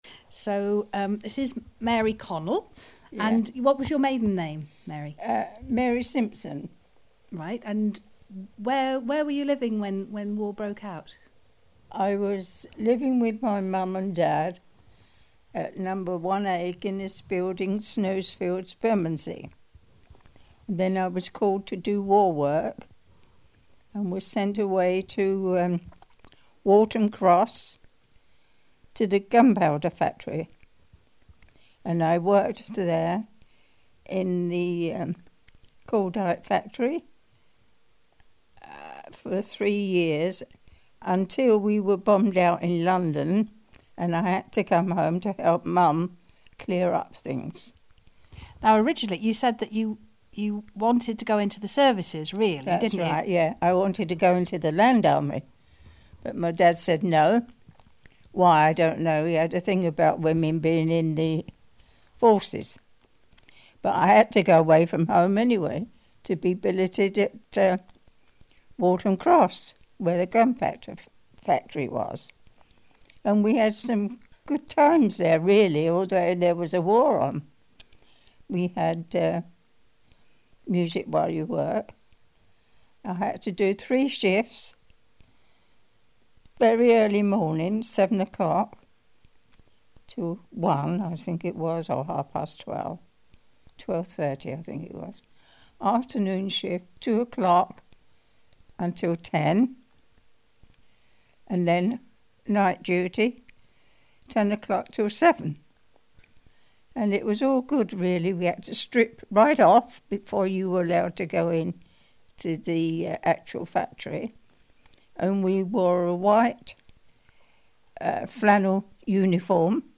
WAOH - Waltham Abbey Oral History